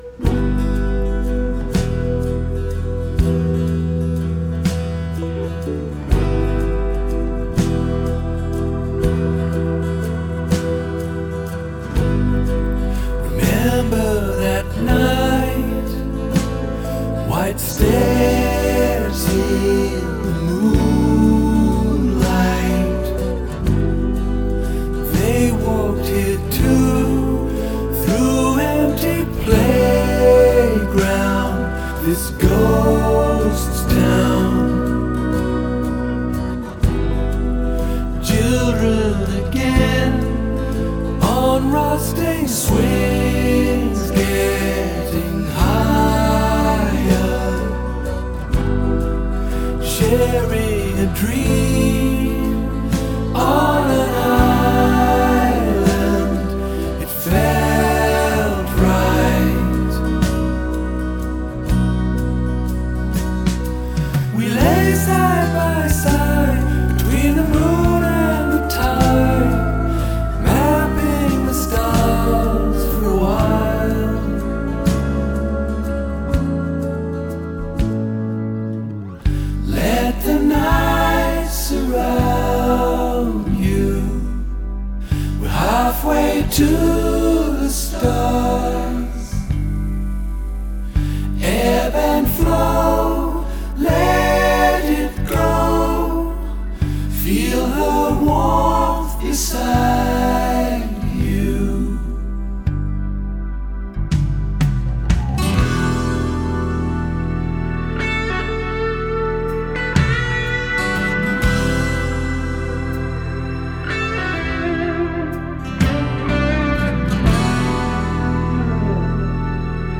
زیبا و خفن...سولوی خودشهبرید دعام کنید